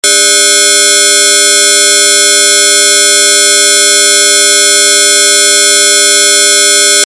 We started by trying to build a single bug-sound using the RTcmix FMINST . instrument.
bug1.mp3